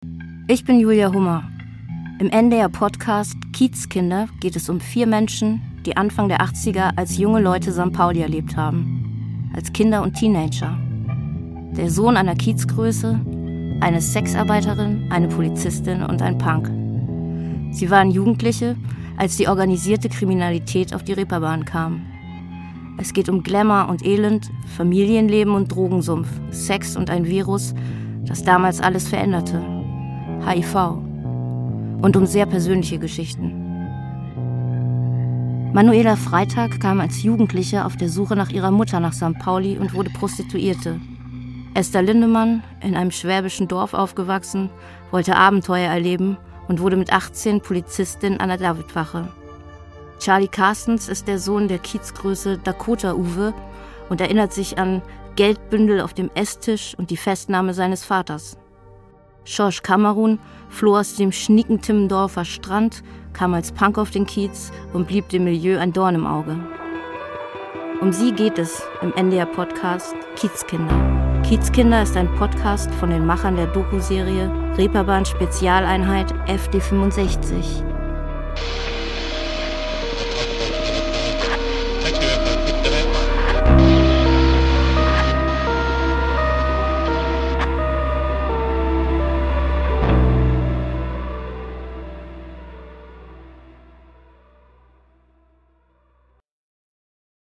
Erzählerin im Podcast: Julia Hummer.